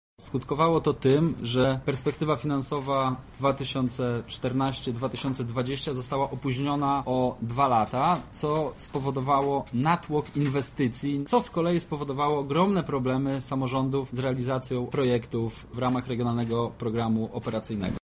Regionalny Program Operacyjny który obecnie jest realizowany jest opóźniony o dwa lata – mówi Przewodniczący Sejmiku Województwa Lubelskiego Michał Mulawa